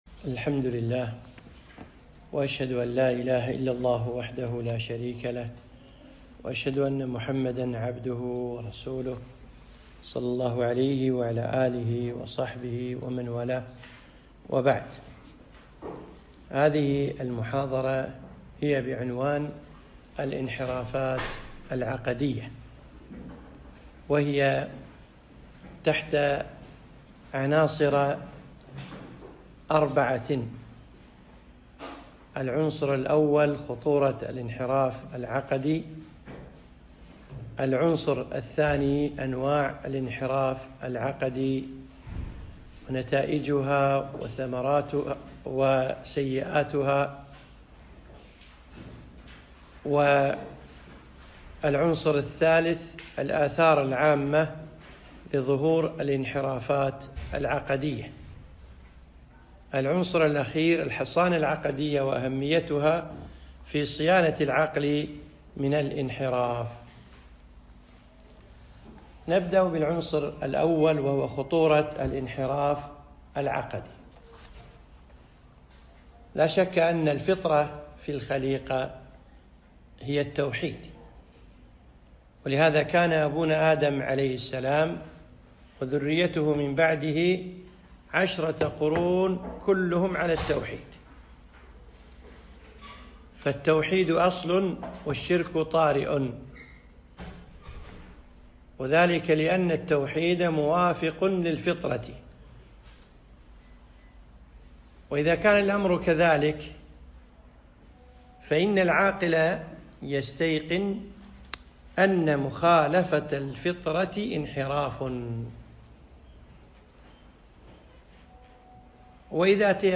محاضرة - الانحرافات العقدية